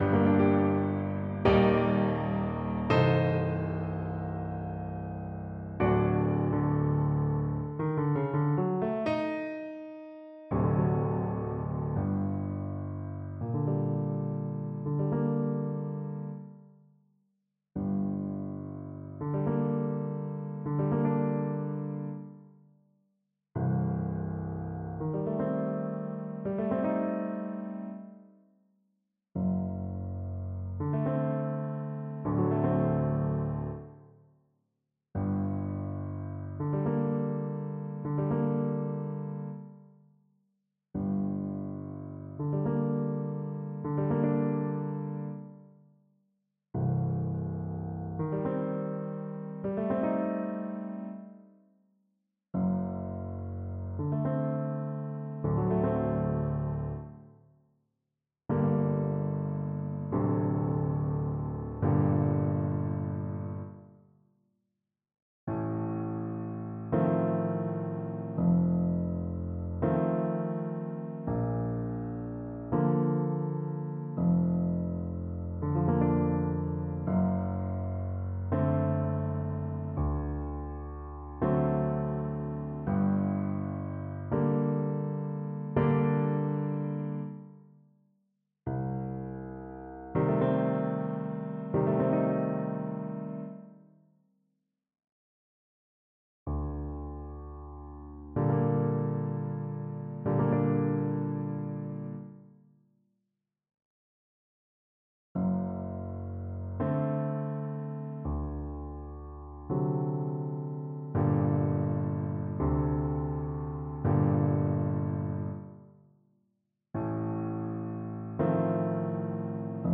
Largo = c.69
2/4 (View more 2/4 Music)
Classical (View more Classical Cello Music)
Gypsy music for cello